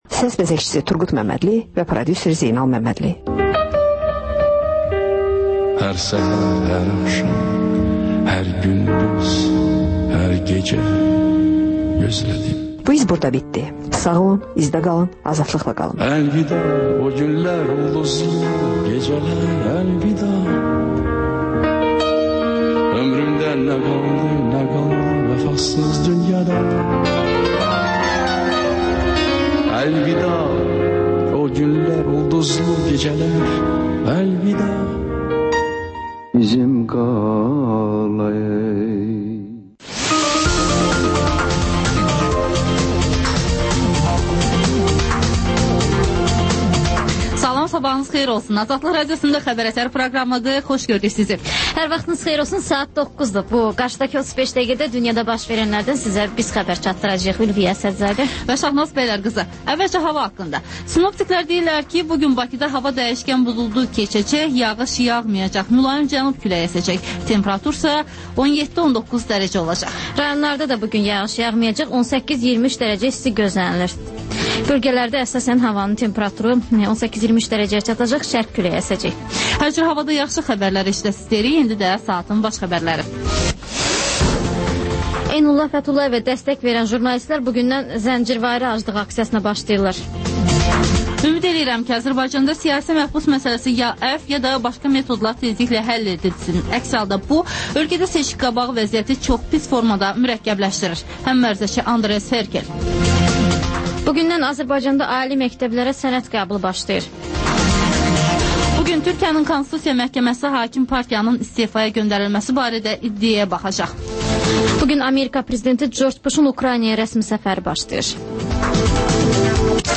Xəbər-ətər: xəbərlər, müsahibələr, sonra PANORAMA verilişi: Həftənin aktual mövzusunun müzakirəsi